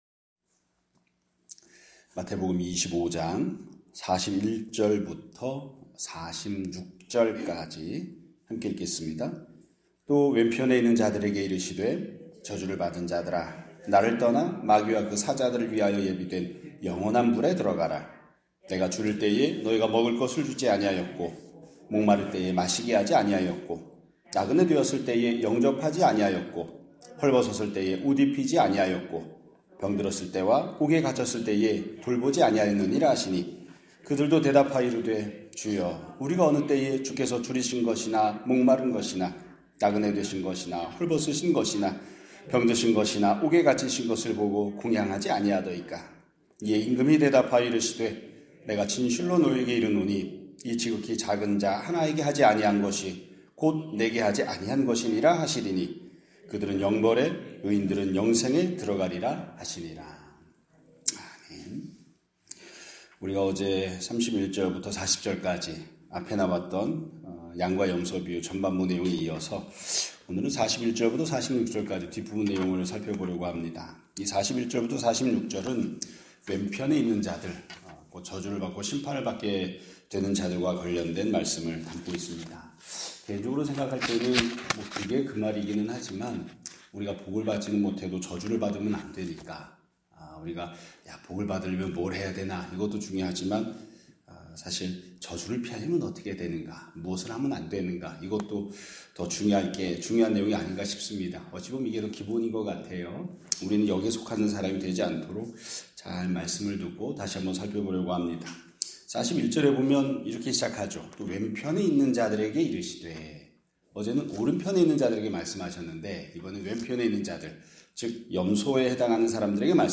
2026년 3월 24일 (화요일) <아침예배> 설교입니다.